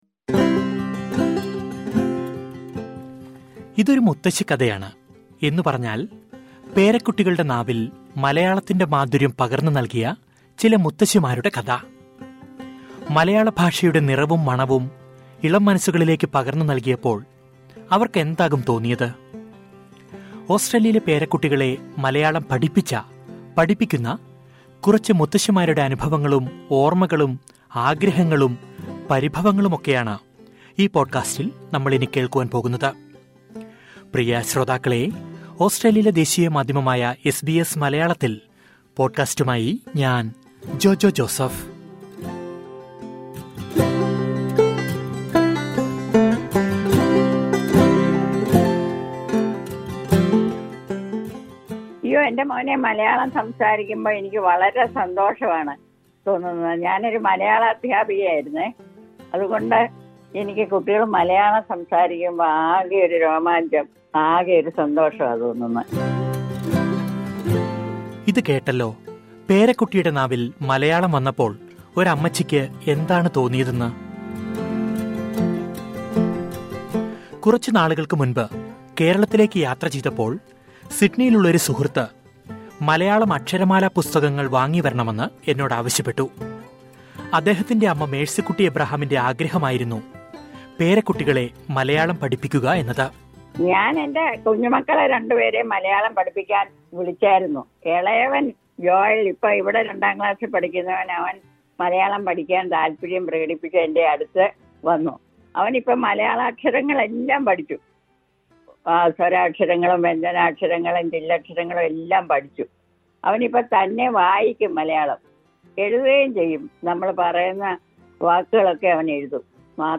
ഓസ്‌ട്രേലിയയിലെ പേരക്കുട്ടികളെ 'മലയാളികളാക്കുന്ന' മുത്തശ്ശിമാര്‍ പറയുന്നു